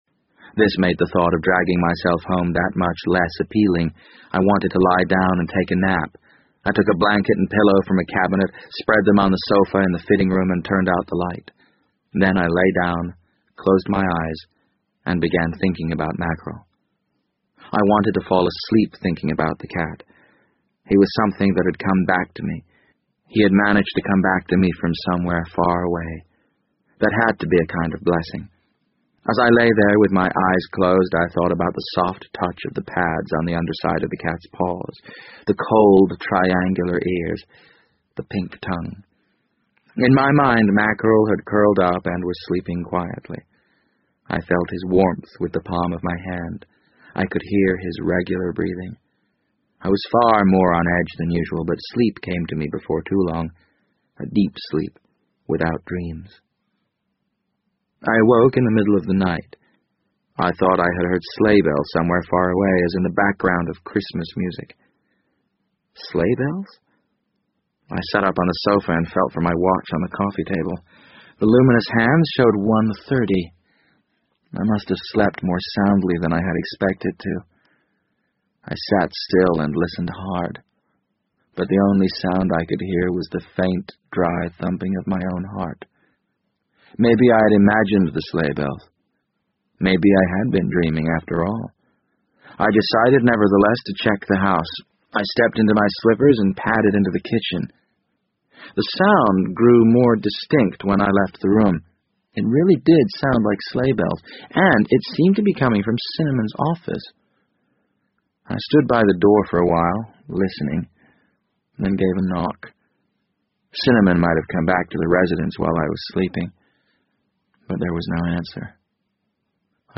BBC英文广播剧在线听 The Wind Up Bird 013 - 4 听力文件下载—在线英语听力室